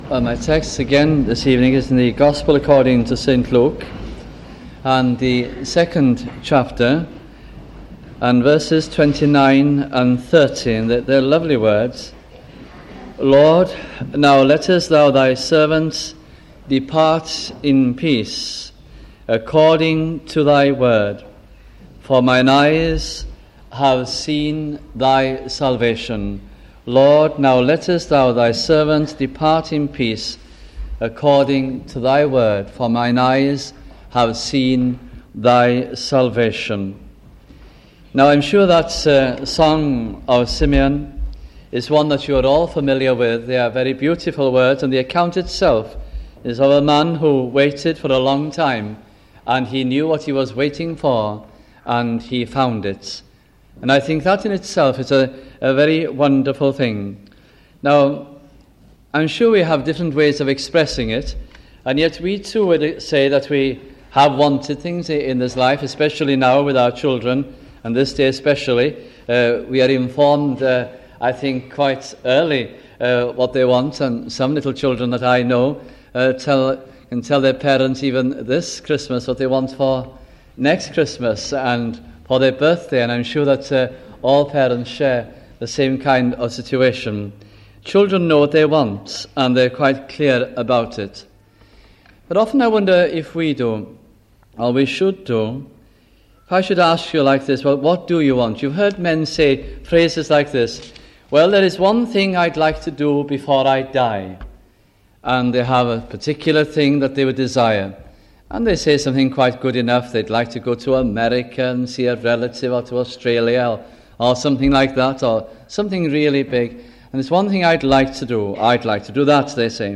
Title: Christmas Morning Service 1977